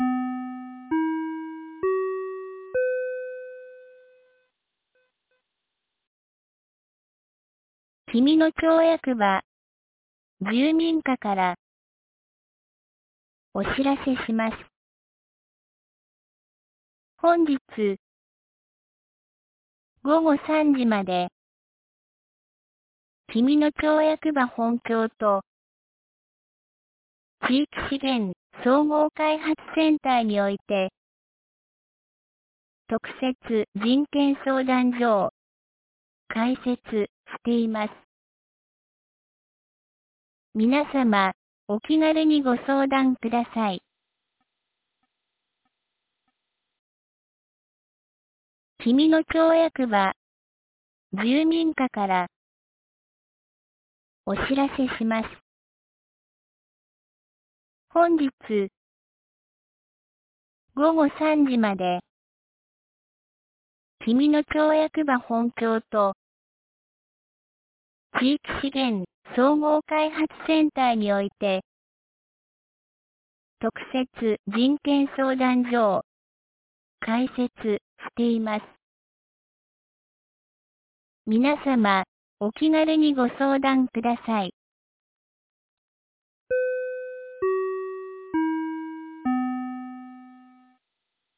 2023年12月07日 12時31分に、紀美野町より全地区へ放送がありました。